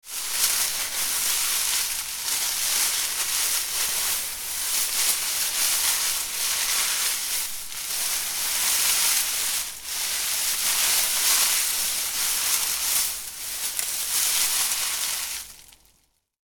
New Year Rustling Tinsel Sound Effect
Description: New year rustling tinsel sound effect. This holiday decoration rustling sound captures the gentle noise of Christmas ornaments, tinsel, and garlands as they move.
New-year-rustling-tinsel-sound-effect.mp3